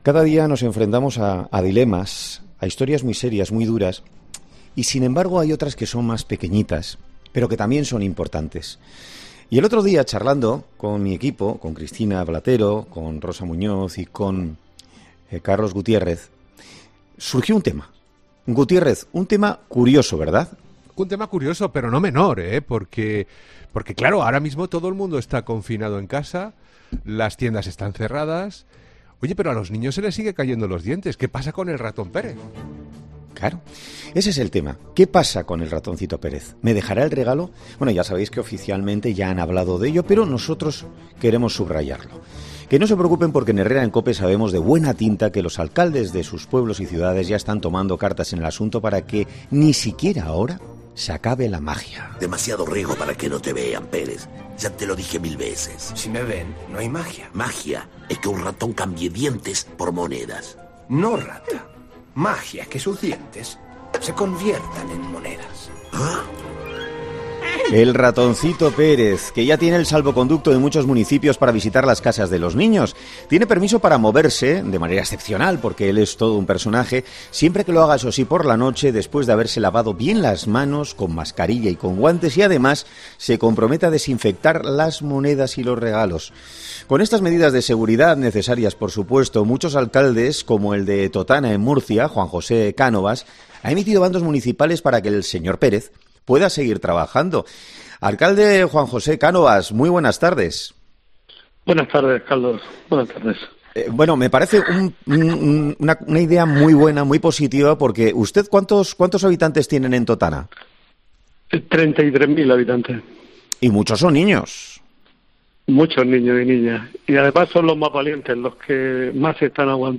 Y en COPE hemos hablado con algunos de los alcaldes y también hemos escuchado a los niños.